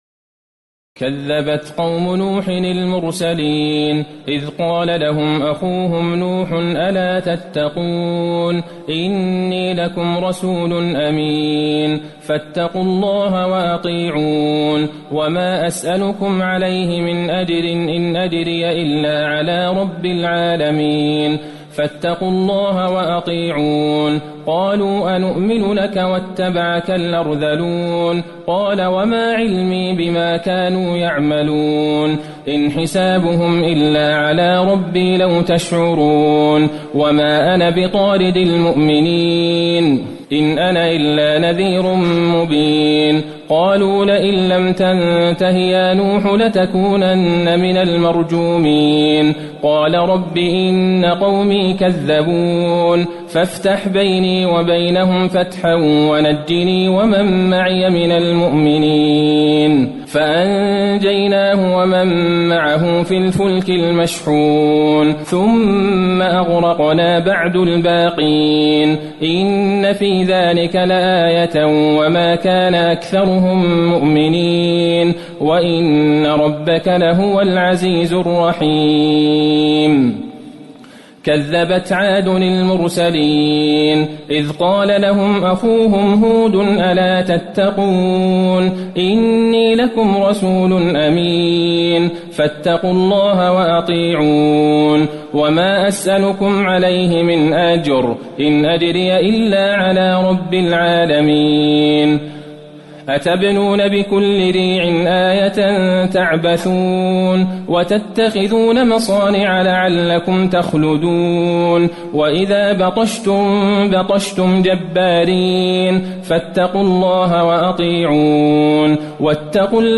تراويح الليلة الثامنة عشر رمضان 1437هـ من سورتي الشعراء (105-227) والنمل (1-58) Taraweeh 18 st night Ramadan 1437H from Surah Ash-Shu'araa and An-Naml > تراويح الحرم النبوي عام 1437 🕌 > التراويح - تلاوات الحرمين